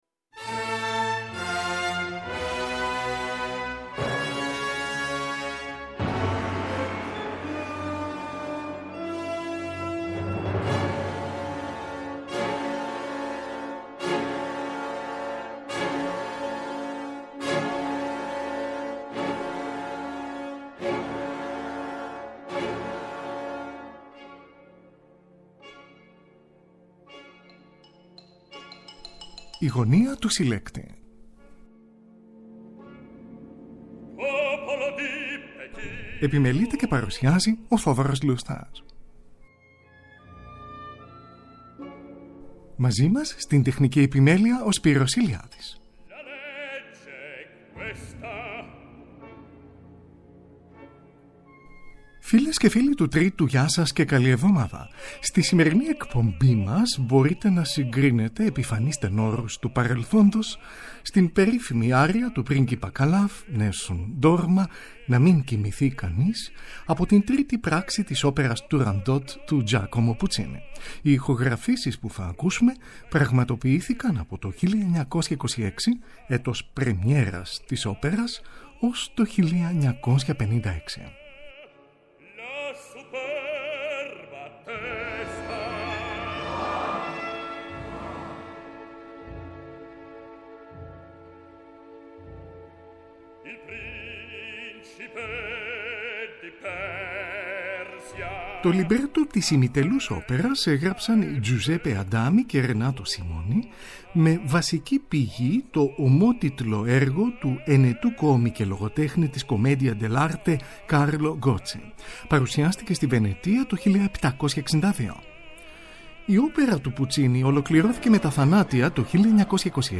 Η ΔΗΜΟΦΙΛΗΣ ΑΡΙΑ ΤΟΥ ΠΡΙΓΚΙΠΑ CALAF NESSUN DORMA AΠΟ ΤΗΝ ΟΠΕΡΑ TURANDOT TOY GIACOMO PUCCINI ΜΕ 15 ΕΠΙΦΑΝΕΙΣ ΤΕΝΟΡΟΥΣ ΤΟΥ ΠΑΡΕΛΘΟΝΤΟΣ